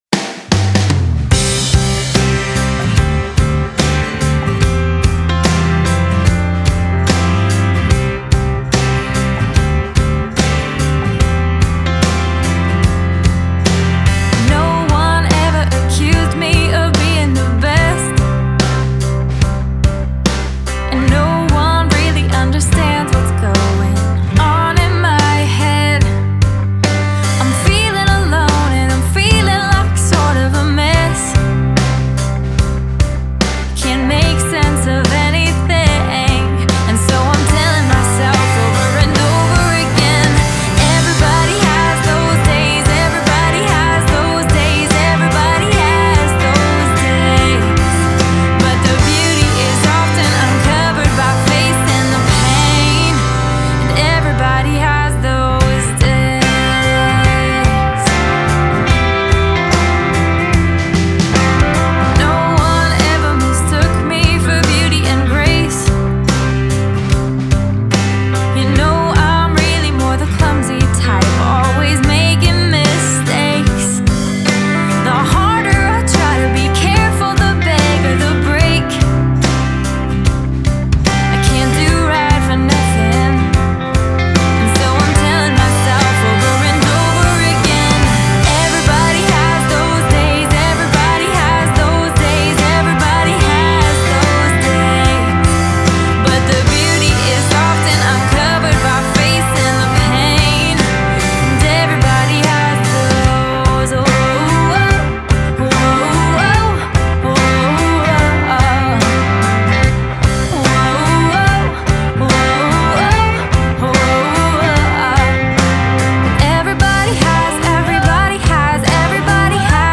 Genre: Christian/Indiepop/Piano Rock/Female Vocal